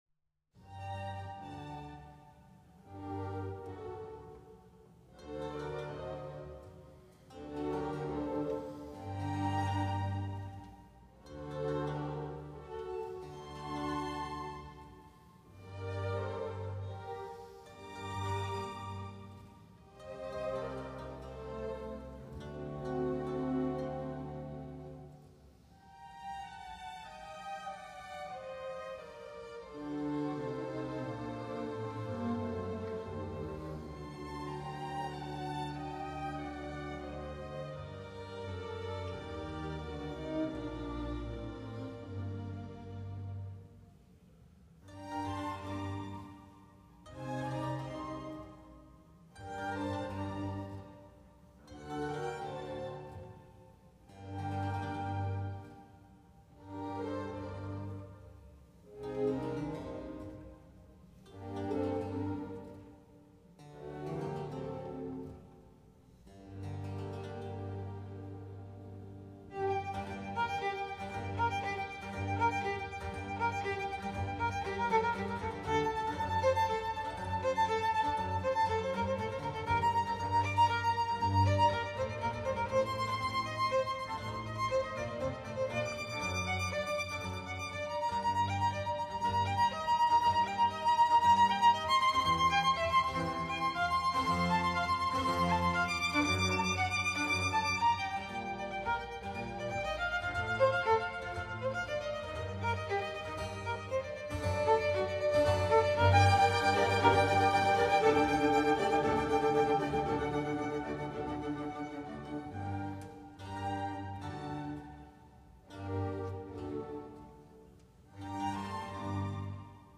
G小调，作品第8号，第二首
第一乐章：夏日炎炎，不太快的快板
独奏小提琴的一个比较活泼的乐句描绘杜鹃，斑鸠和金翅雀的叫声。微风的柔声细语（小提琴和中提琴）被“狂风”和风神的突然发作所中断，它们是由小提琴声部奔驰着的音阶和整个乐队的突然猛烈的爆发来描绘的。
如泣如诉的小提琴独奏表示。